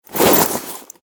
main_equipment_btn.mp3